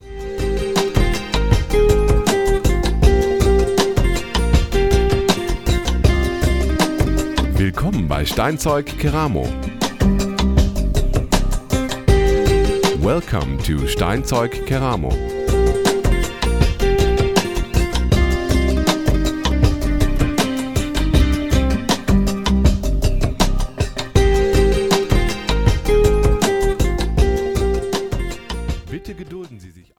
• Telefonschleife